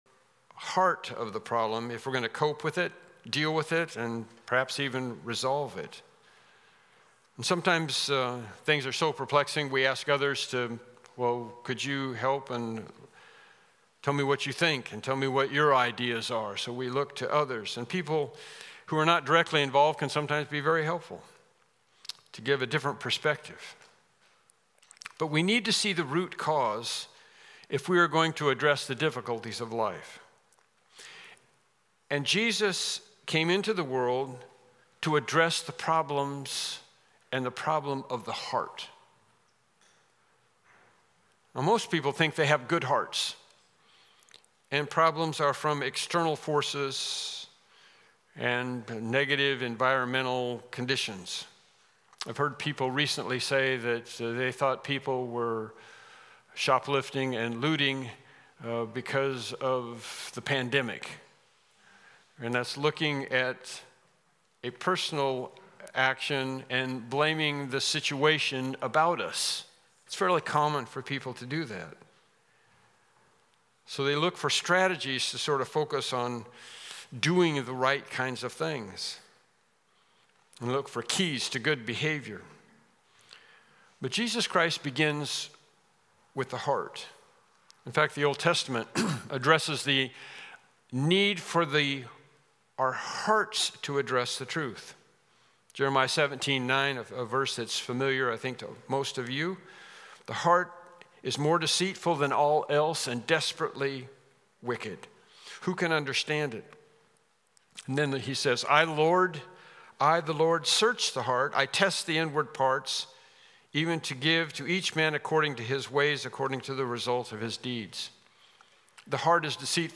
Passage: Mark 7:9-16 Service Type: Morning Worship Service